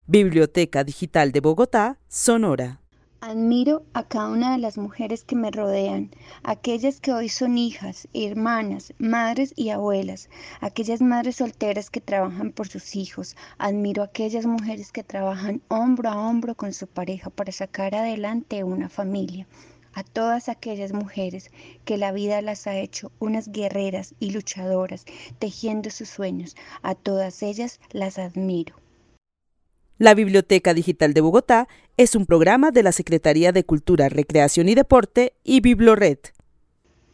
Narración oral de una mujer que vive en la ciudad de Bogotá y que admira a cada una de las mujeres que la rodean; aquellas que son hijas, madres y abuelas, las madres solteras que trabajan por sus hijos. Admira también a las mujeres que trabajan de la mano con su pareja para sacar adelante una familia.
El testimonio fue recolectado en el marco del laboratorio de co-creación "Postales sonoras: mujeres escuchando mujeres" de la línea Cultura Digital e Innovación de la Red Distrital de Bibliotecas Públicas de Bogotá - BibloRed.